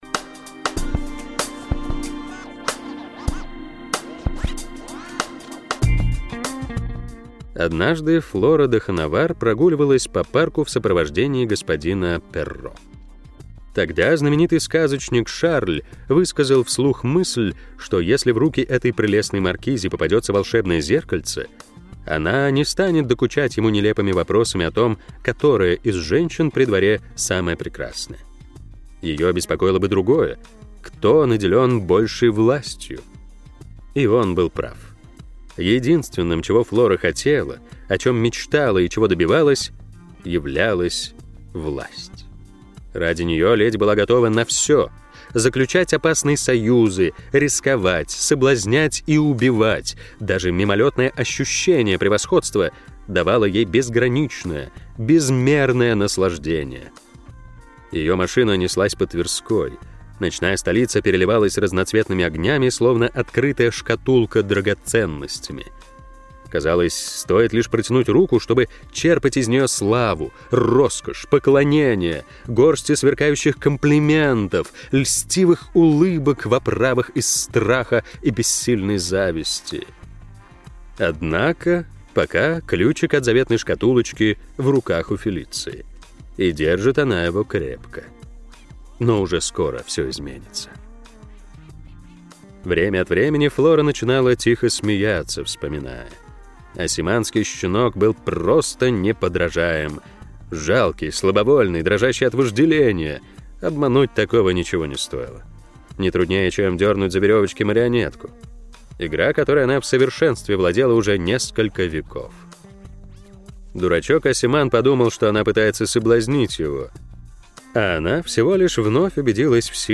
Аудиокнига Темный Охотник | Библиотека аудиокниг